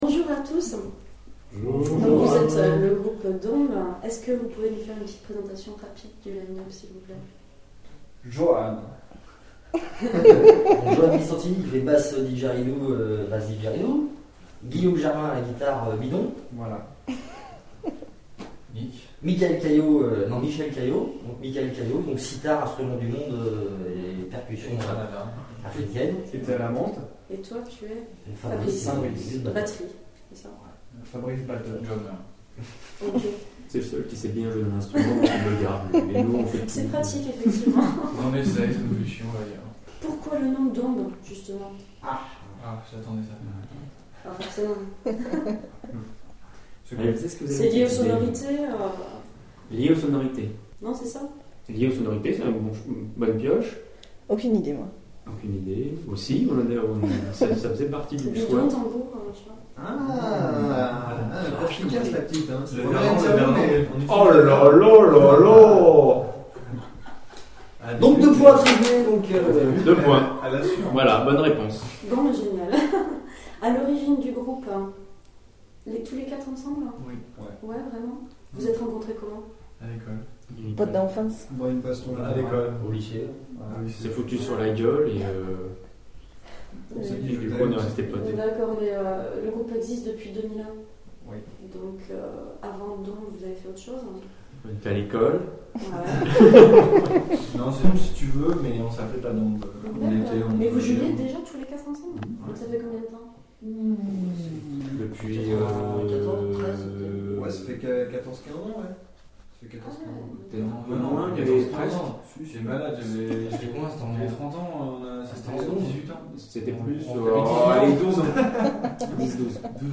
Interview
Interview réalisée le 22/02/2008 à Imaj-Cri’art.